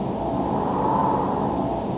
windfly.wav